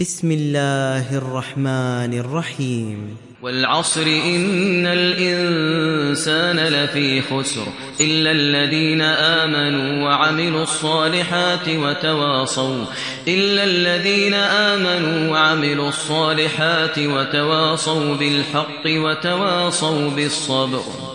دانلود سوره العصر mp3 ماهر المعيقلي روایت حفص از عاصم, قرآن را دانلود کنید و گوش کن mp3 ، لینک مستقیم کامل